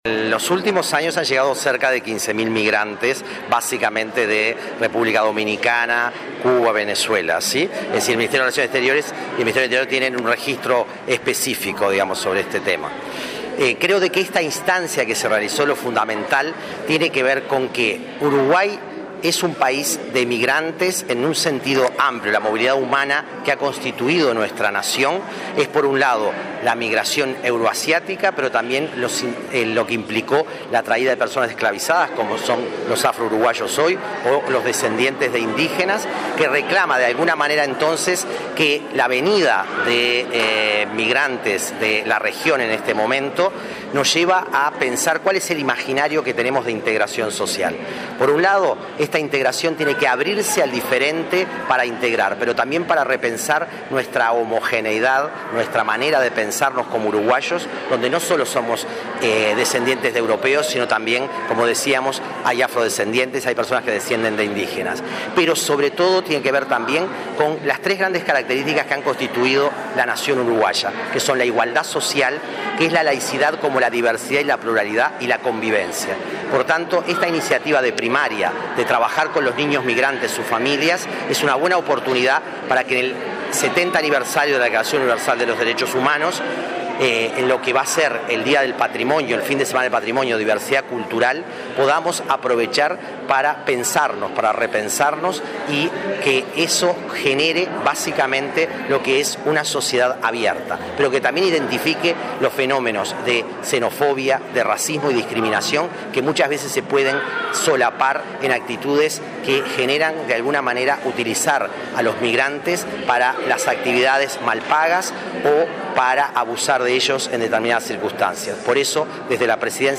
“Uruguay es un país de migrantes en un sentido amplio”, sostuvo el secretario de Derechos Humanos de Presidencia de la República, Nelson Villarreal, al participar de la presentación, junto con Primaria, de la Comisión de Migraciones. Subrayó que en los últimos años llegaron al país cerca de 15.000 migrantes y dijo que todo niño y joven migrante tiene derecho a ingresar al sistema educativo aunque no tenga cédula de identidad.